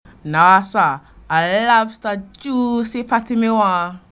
Examples of Jamaican English
//naː sa/a ʹlaːbstə ʹdʒuːsi ʹpati mi waː//